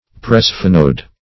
Presphenoid \Pre*sphe"noid\, a. (Anat.)